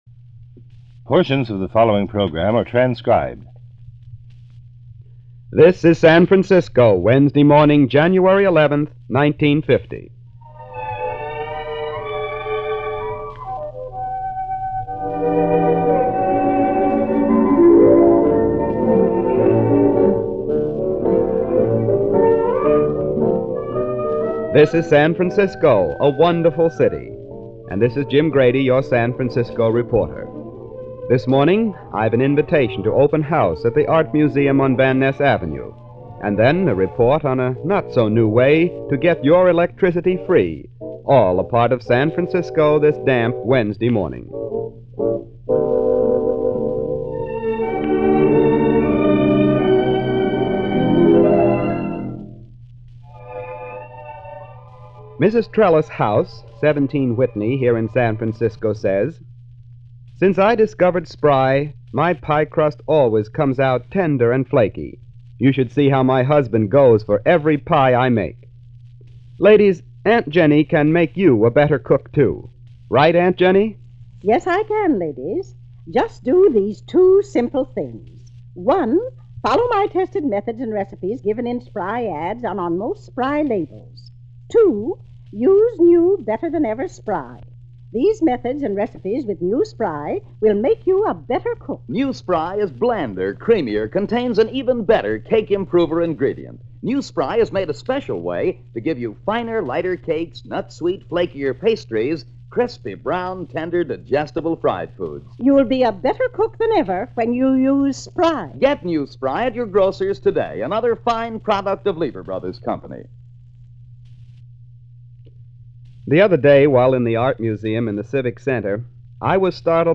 For an example of what a typical day sounded like in a typical city in a typical year, here is a broadcast from KCBS-AM in San Francisco. A morning show called This Is San Francisco.